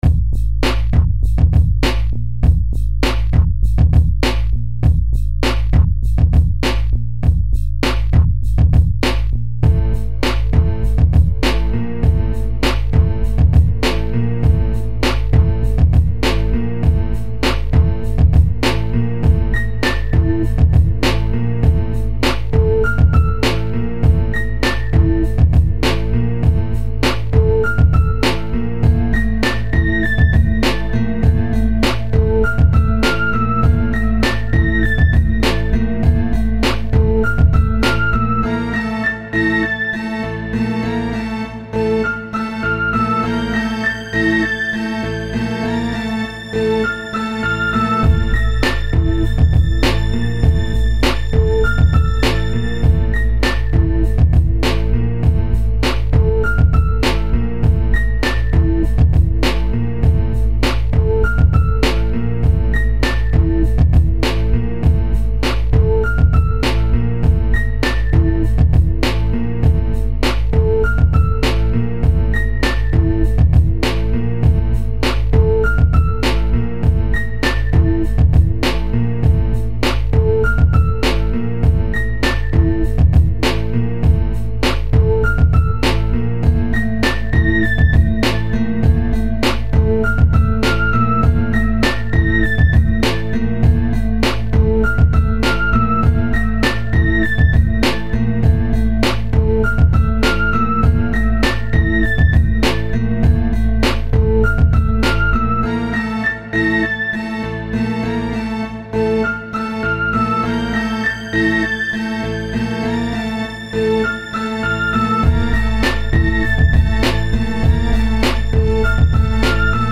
3D Spatial Sounds